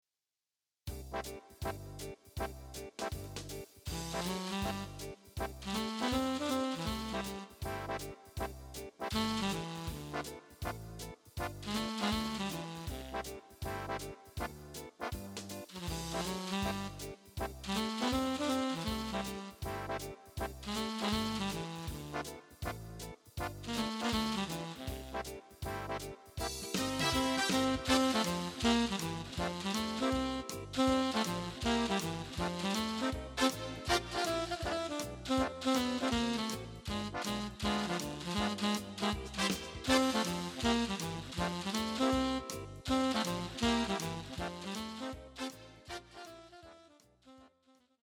Keyboard TYROS, musikalisches Multitalent
September 2007 im Jazzkeller Hanau